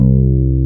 BAS.FRETC2-L.wav